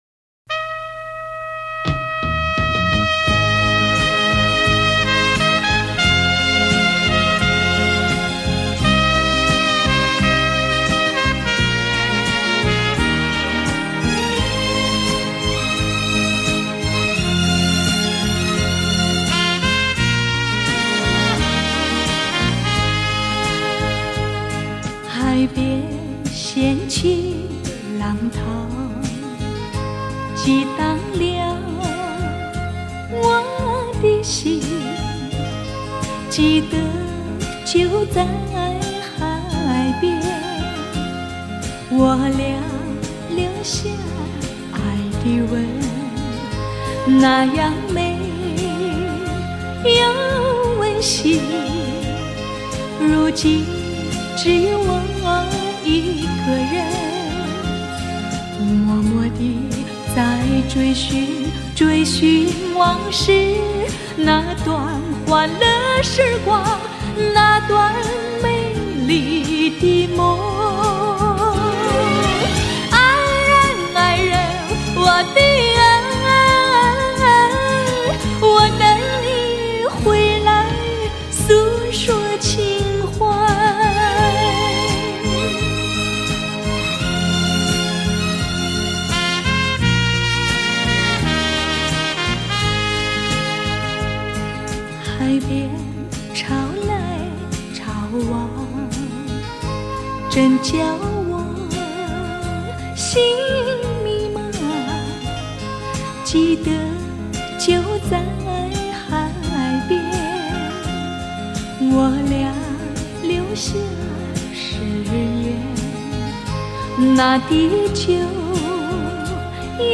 原音重现
一个如水的女子，用缠绵悱恻的歌声诠释着